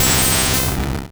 Cri de Tentacruel dans Pokémon Rouge et Bleu.